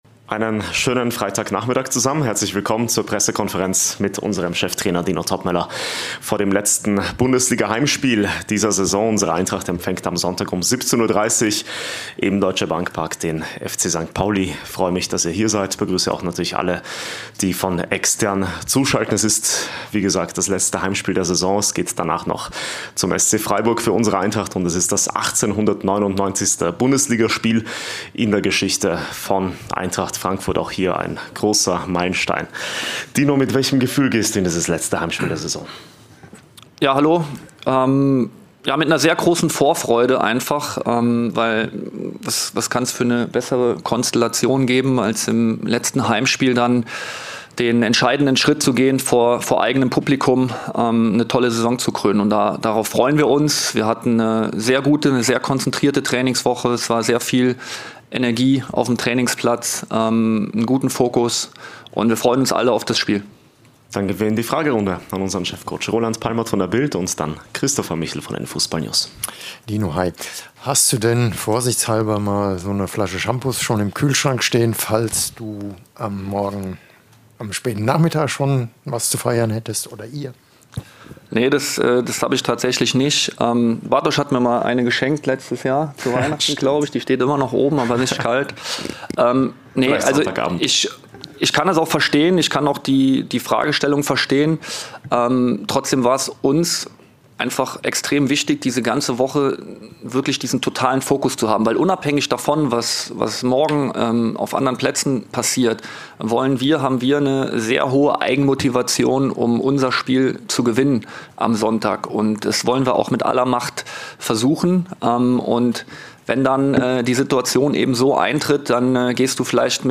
Cheftrainer Dino Toppmöller zum letzten Heimspiel der Saison bei der Pressekonferenz vor St. Pauli.